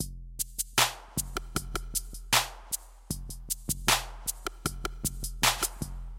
节拍 " 能量节拍3000
描述：走得慢
Tag: 电子乐 说唱 良好的